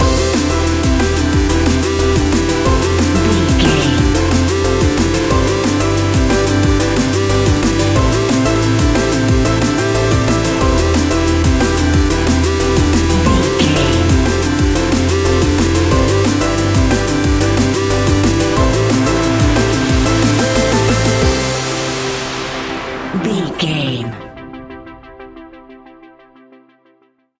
Ionian/Major
Fast
groovy
uplifting
futuristic
driving
energetic
repetitive
drum machine
synthesiser
electronic
sub bass
synth leads
synth bass